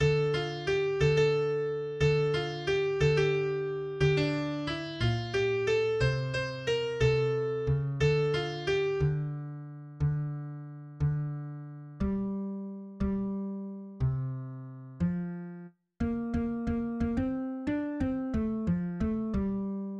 \new Staff  \with {midiInstrument = #"acoustic bass"}